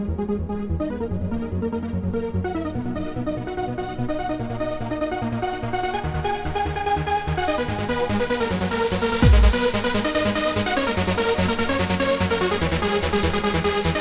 Easy Trance track from 2002 - help needed
Taken from a DJ set in 2002 - any idea what this is at all?